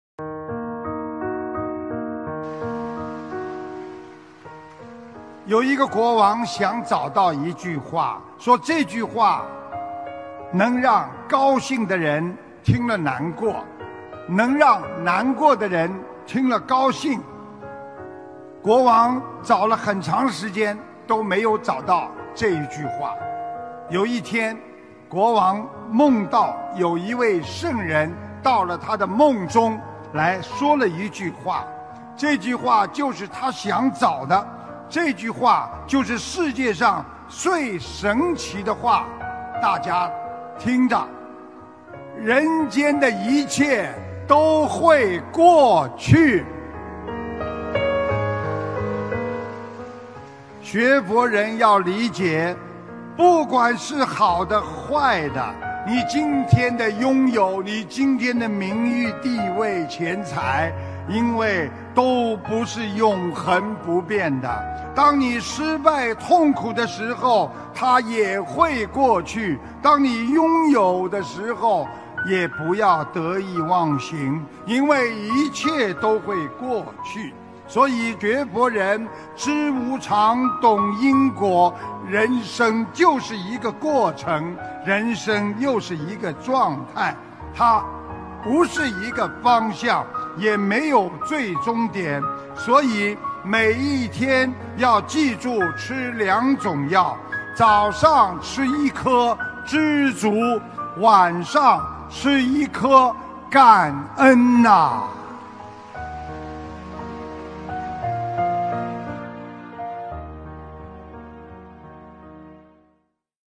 心灵净土»心灵净土 弘法视频 法会节选 视频：118.人间的一切都会过去！